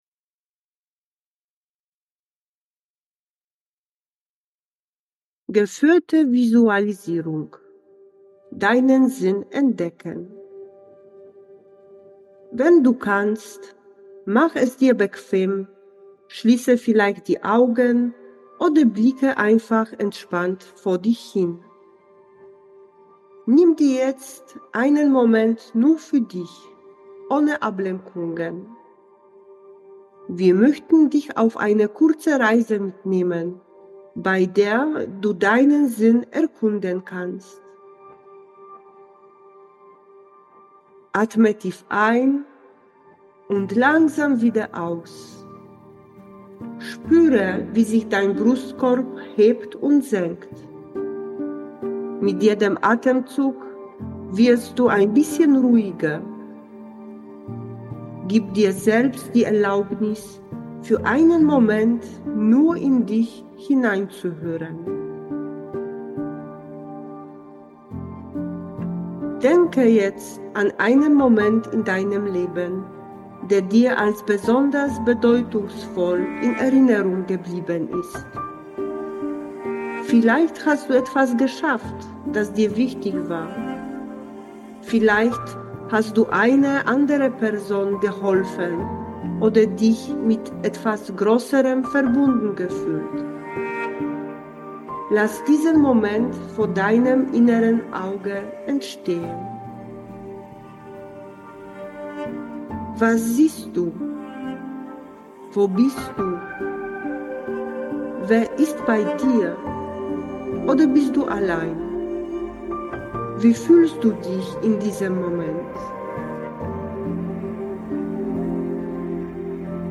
geführte Visualisierung